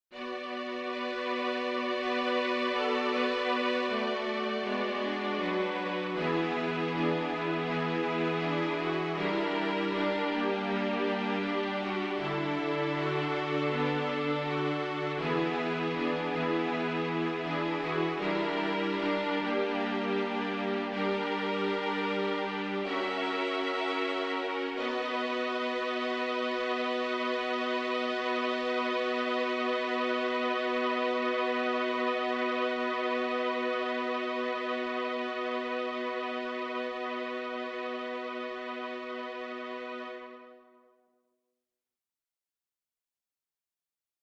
Key written in: B Minor
How many parts: 4
Type: Female Barbershop (incl. SAI, HI, etc)
All Parts mix: